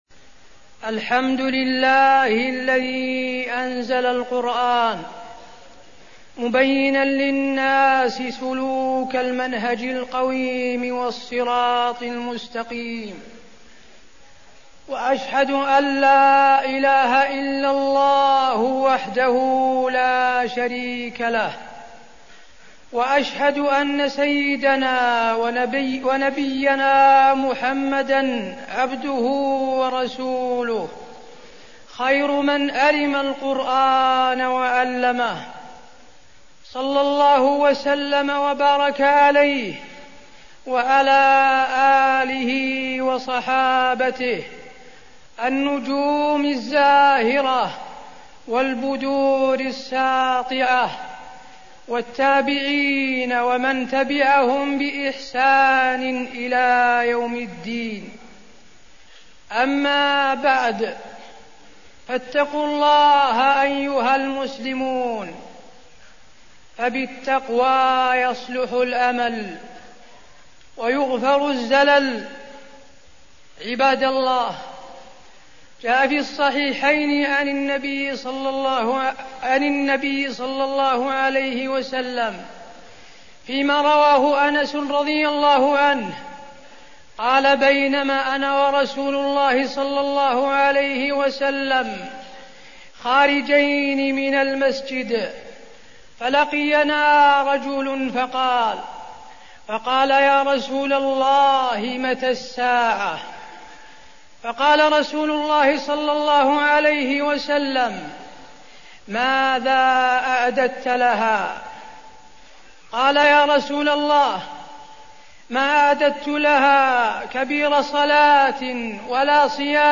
تاريخ النشر ١١ رمضان ١٤١٨ هـ المكان: المسجد النبوي الشيخ: فضيلة الشيخ د. حسين بن عبدالعزيز آل الشيخ فضيلة الشيخ د. حسين بن عبدالعزيز آل الشيخ فضل قراءة القرآن في رمضان